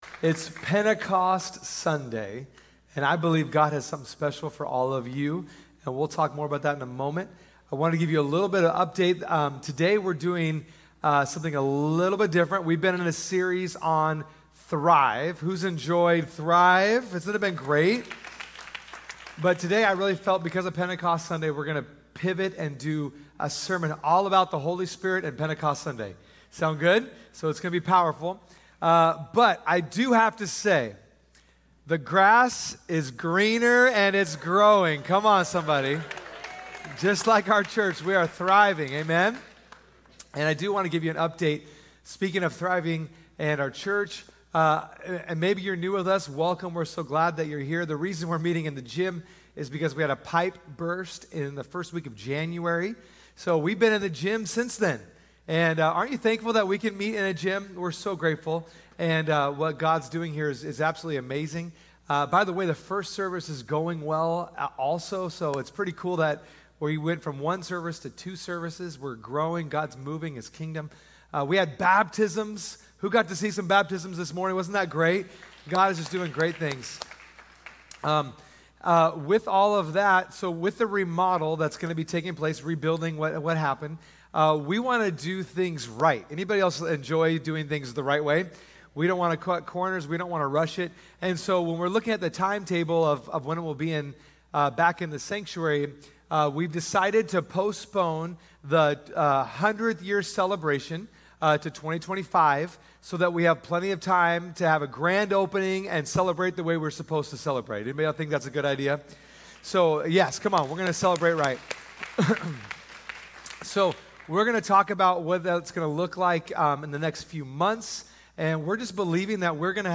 "Pentecost Sunday" | Sunday Message